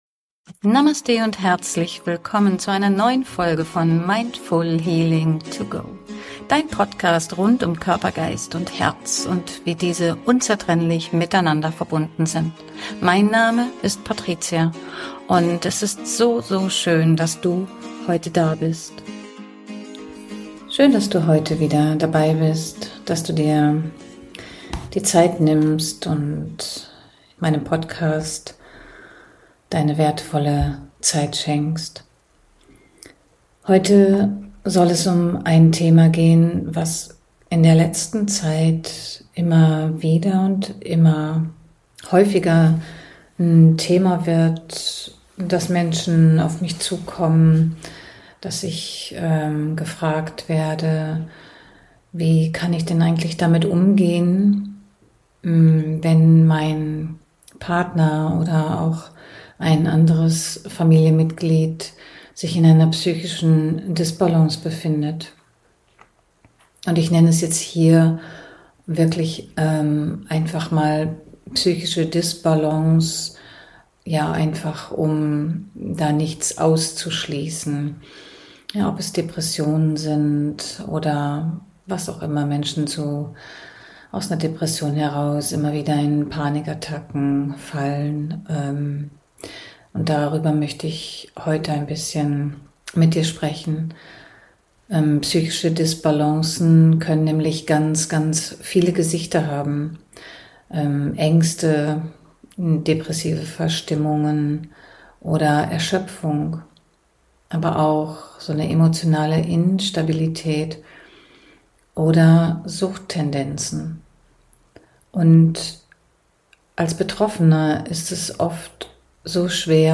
Zum Abschluss begleite ich dich außerdem in eine kurze Meditation, die dir Ruhe, Stabilität und Zuversicht schenkt.